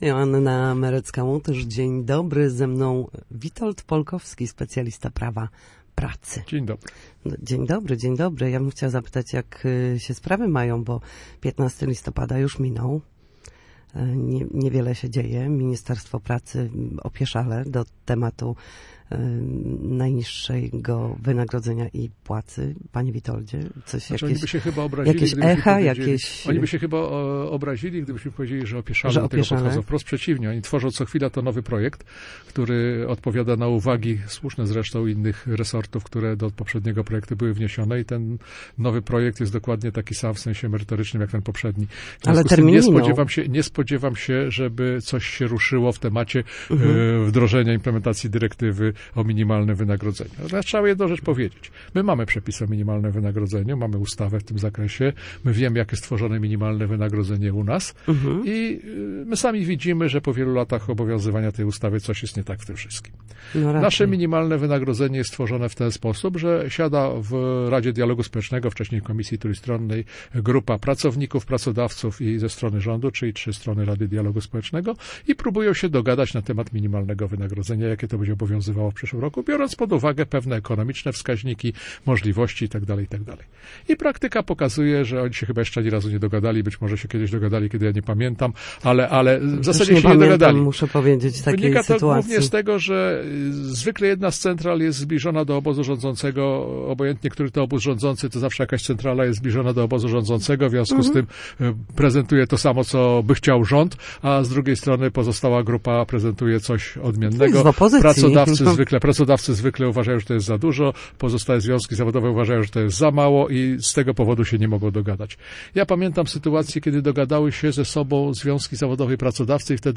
W każdy wtorek po godzinie 13 na antenie Studia Słupsk przybliżamy państwu zagadnienia dotyczące prawa pracy.